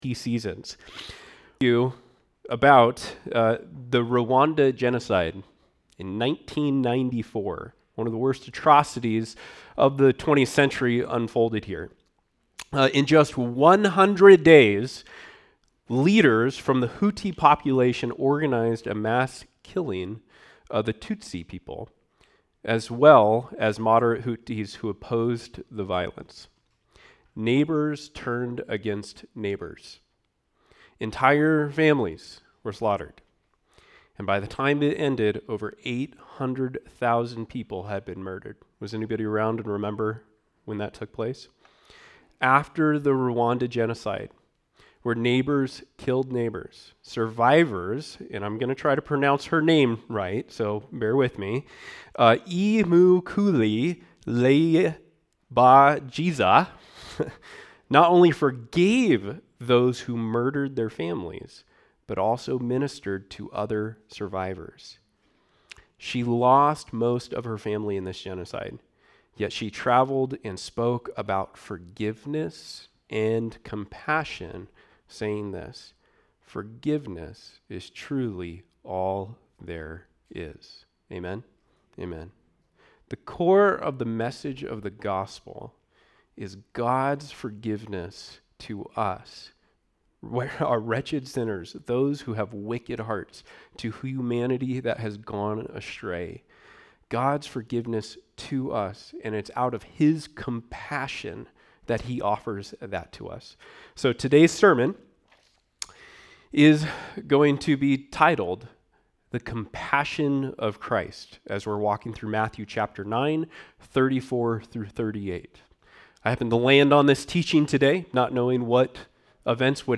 A message from the series "Matthew."